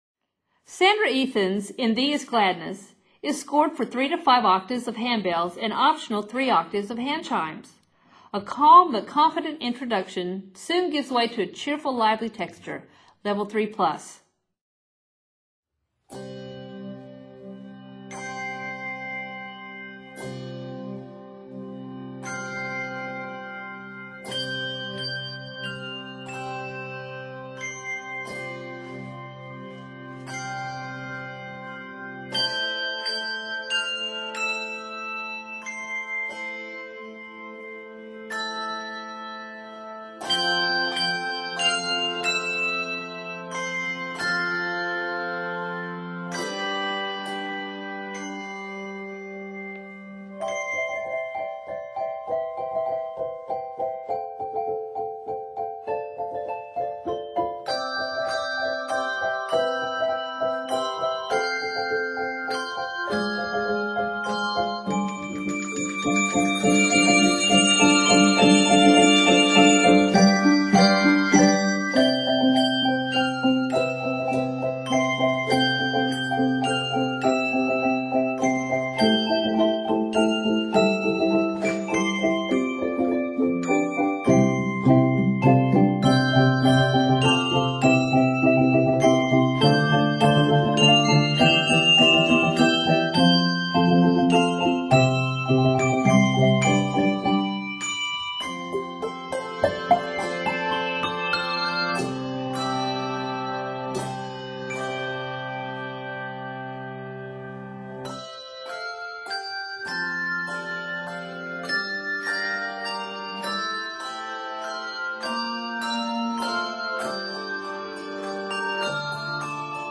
is scored in C Major and D Major.